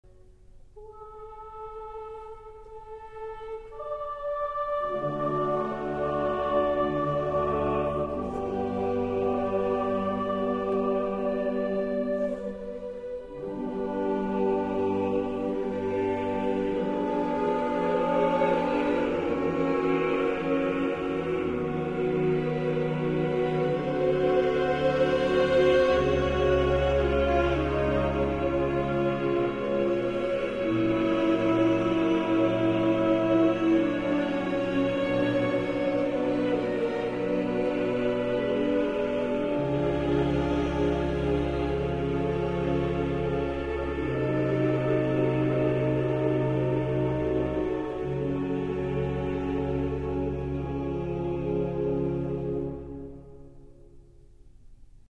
Coro della radio Svizzera - Diego Fasolis & Aura Musicale Budapest - René Clemencic
ATTENTION ! Dans cet enregistrement, on utilise le "diapason baroque" (La415).
On l'entend donc environ un demi-ton en dessous du diapason actuel (La440) que l'on emploiera et qui est celui des fichiers midi et virtual voice.